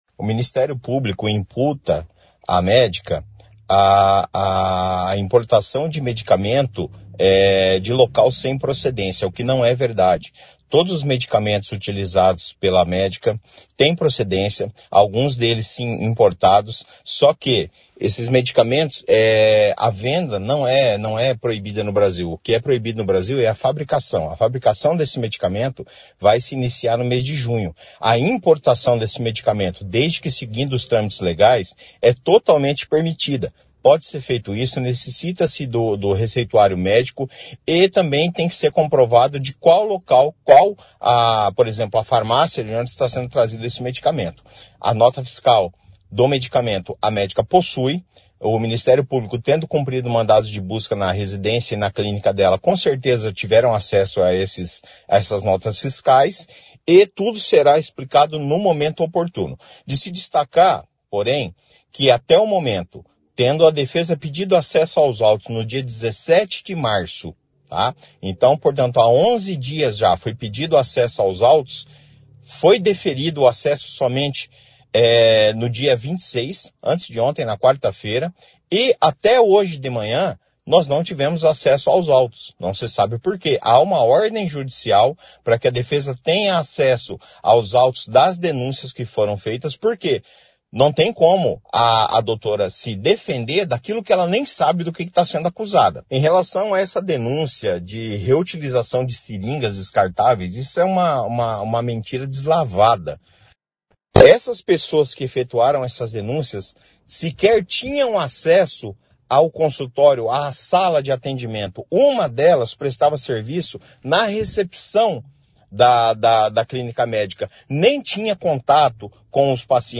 O advogado também falou sobre a denúncia de reutilização de seringas e sobre a suspensão do registro profissional da médica. Ouça a entrevista: